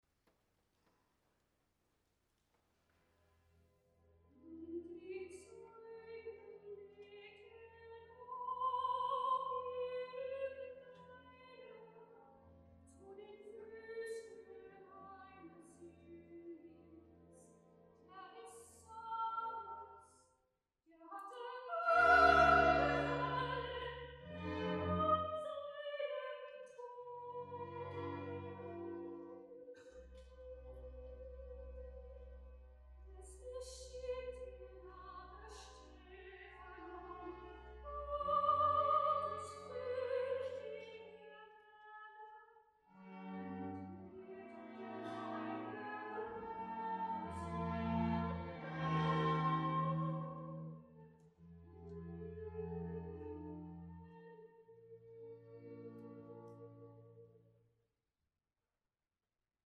8 februari 2025 uitvoering Paulus van F. Mendelssohn-Bartholdy in de Bethlehemkerk te Papendrecht ism COV Laudate Hardinxveld-Giessendam en Barokconsort Musica Aeterna